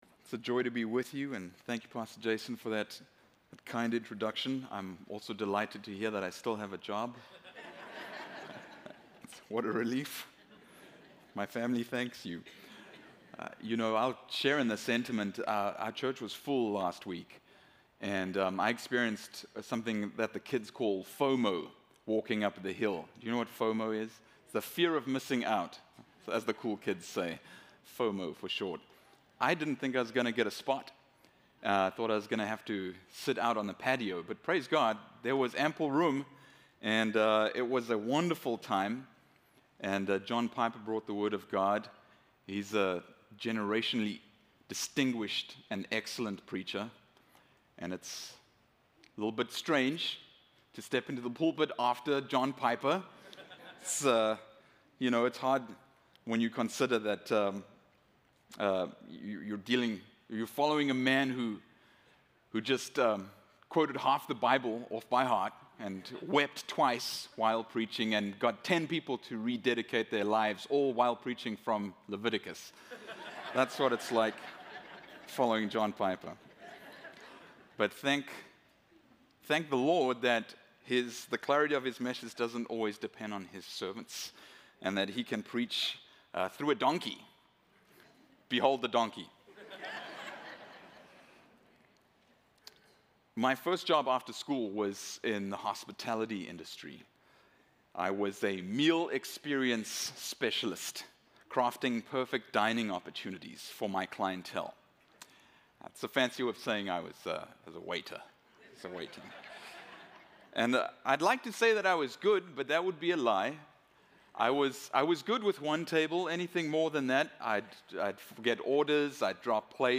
Sermons from the Pulpit of Kindred Community Church